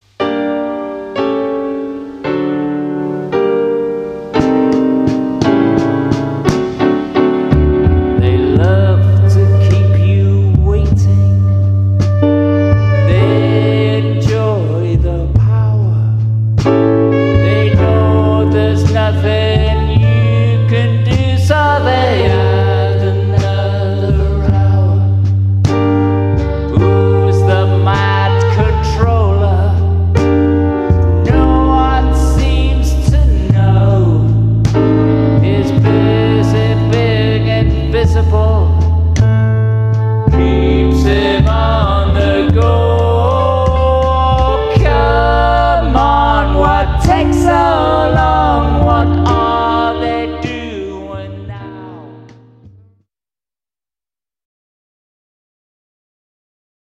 vocals, guitar, percussion
piano, bass, saxophone